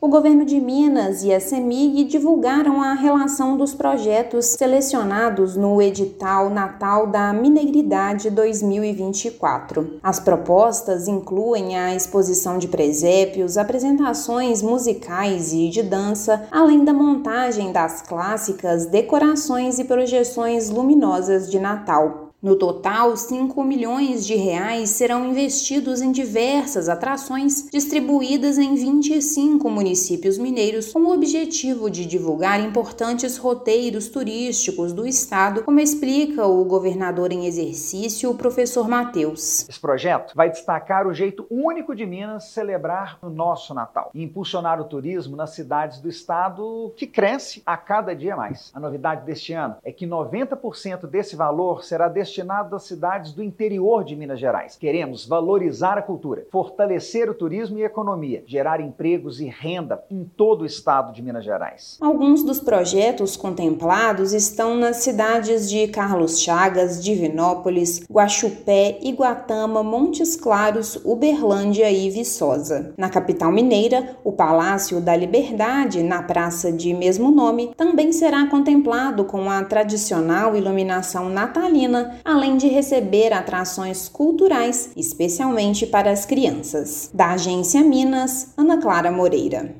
[RÁDIO] Governo de Minas e Cemig anunciam cidades contempladas no edital do Natal da Mineiridade 2024
Municípios vão receber R$ 5 milhões para iluminação e atividades culturais ligadas ao período natalino. Ouça matéria de rádio.